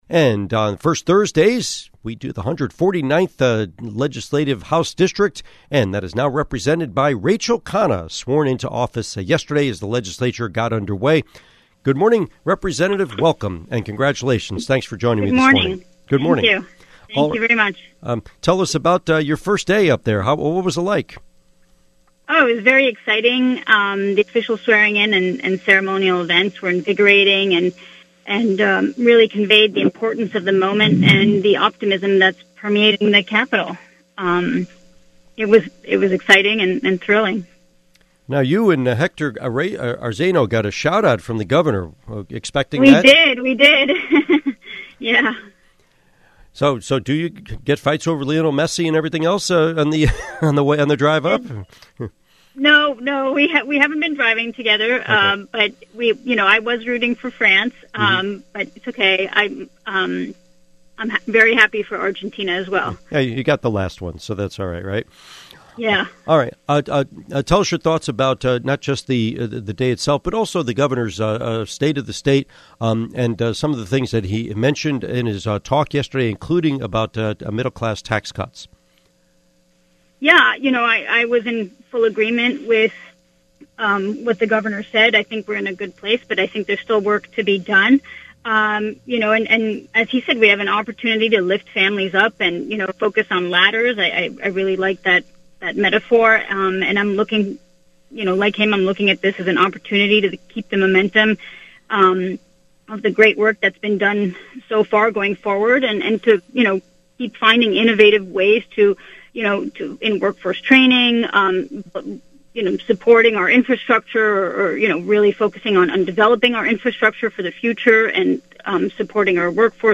Interview with State Representative Rachel Khanna